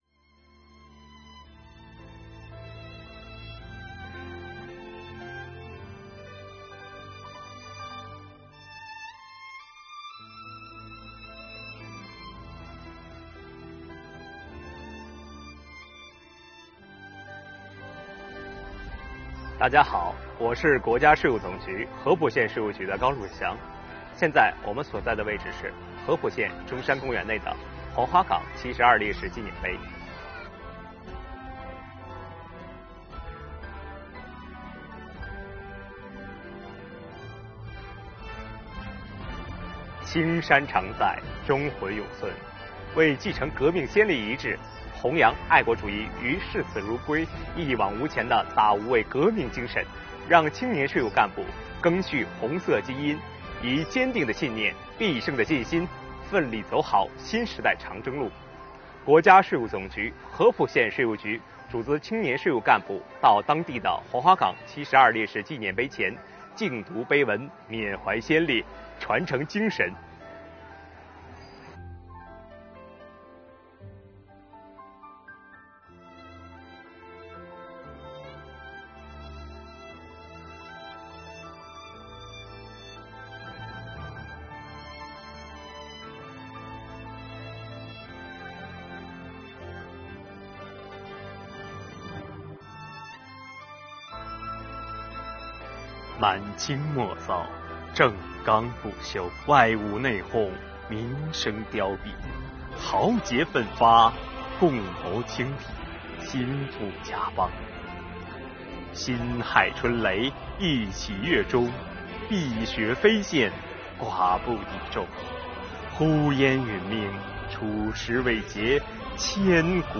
为继承革命先烈遗志，弘扬爱国主义与视死如归、一往无前的大无畏革命精神，让青年税务干部赓续红色基因，以坚定的信念、必胜的信心奋力走好新时代长征路，国家税务总局合浦县税务局组织青年干部到当地黄花岗七十二烈士纪念碑前，敬读碑文，缅怀先烈，传承精神。